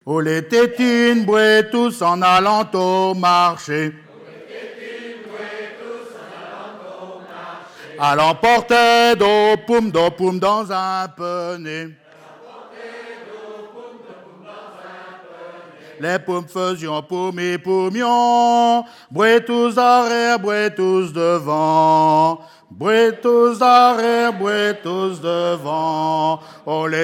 Genre énumérative
chansons traditionnelles lors d'un concert associant personnes ressources et continuateurs